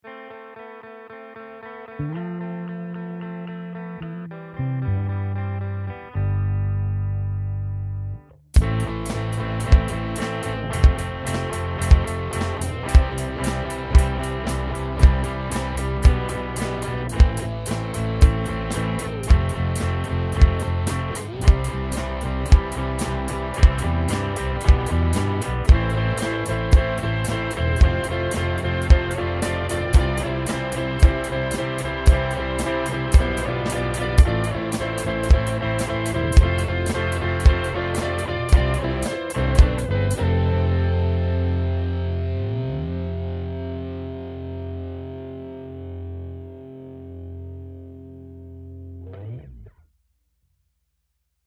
ROCK BEAT